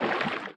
Sfx_creature_brinewing_swim_fast_02.ogg